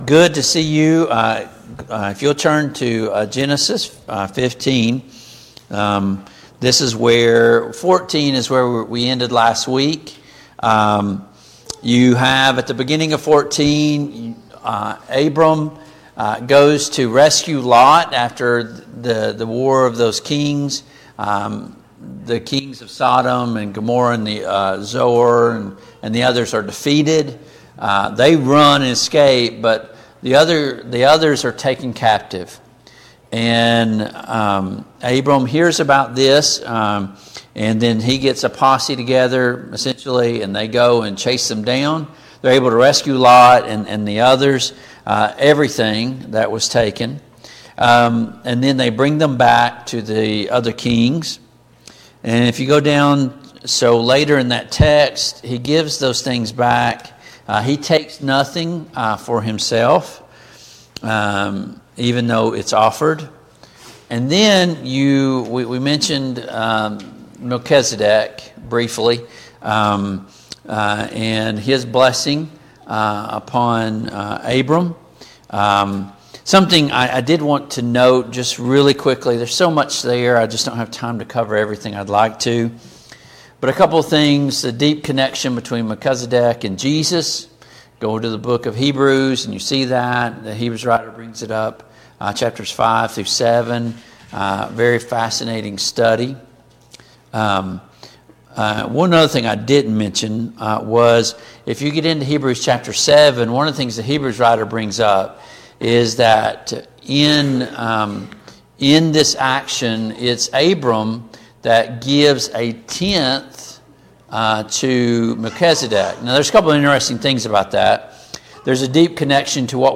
Study of Genesis Passage: Genesis 14, Genesis 15, Genesis 16 Service Type: Family Bible Hour « Did Jesus go to Hell after He died on the cross?